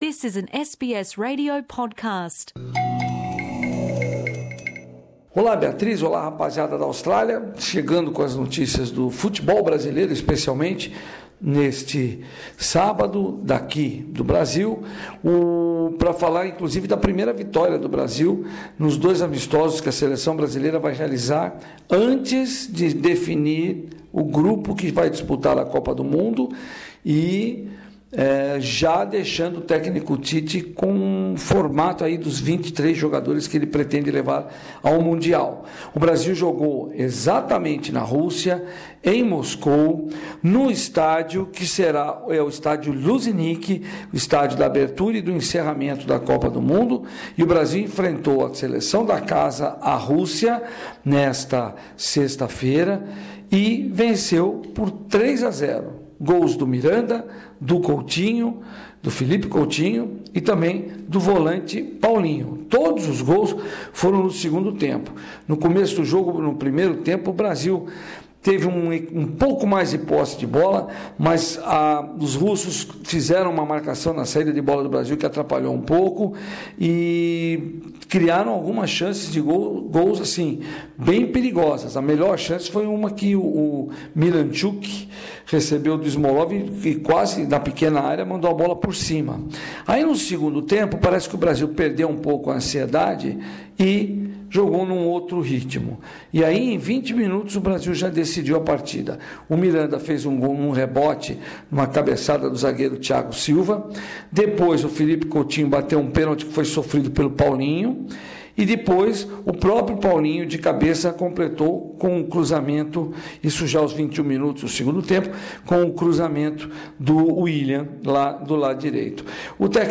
Boletim esportivo semanal